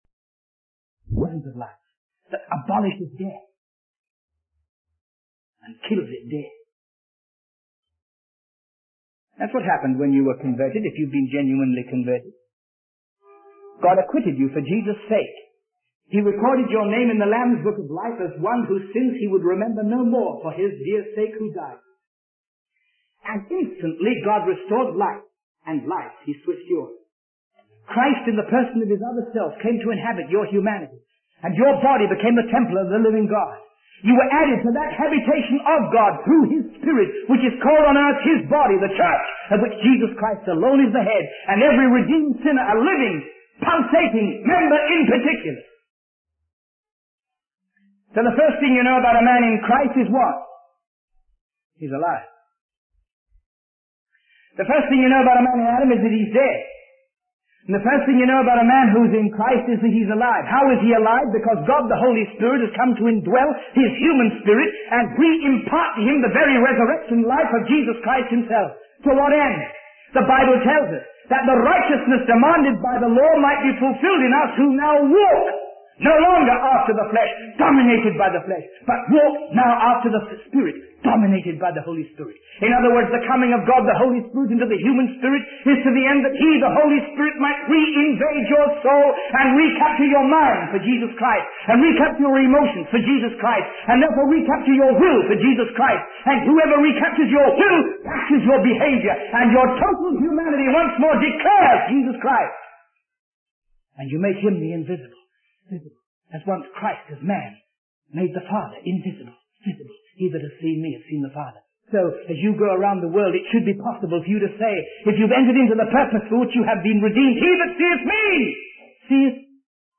In this sermon, the preacher emphasizes the importance of surrendering one's life to God.